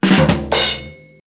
1 channel
rimsht04.mp3